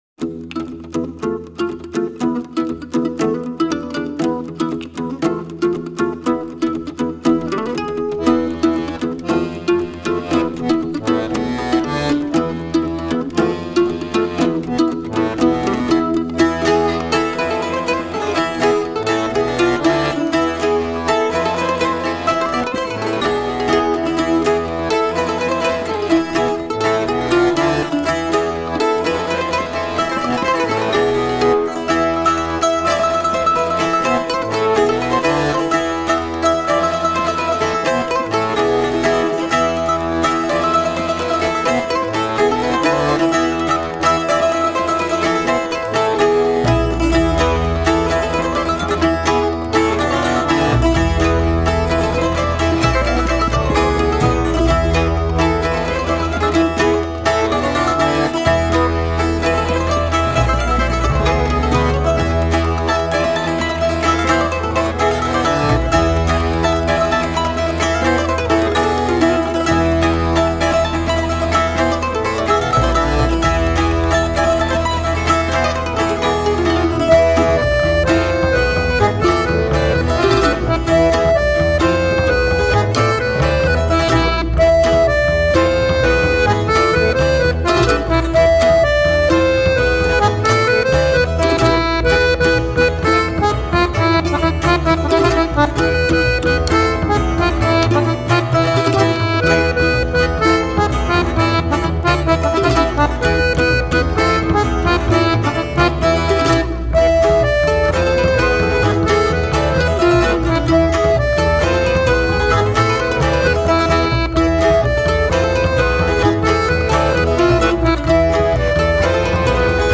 bourrée à 2 temps